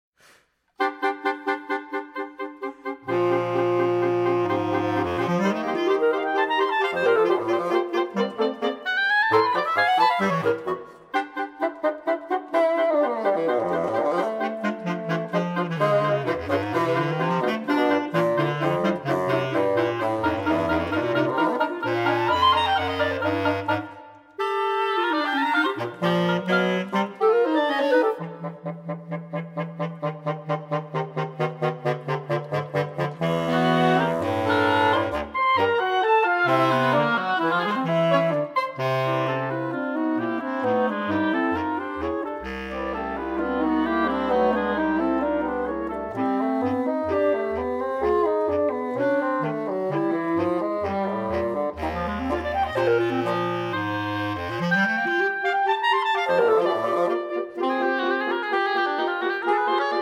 Reed Quintet
Bassoon
Saxophone
Bass Clarinet
Oboe